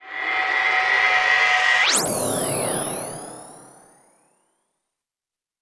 Roland.Juno.D _ Limited Edition _ GM2 SFX Kit _ 17.wav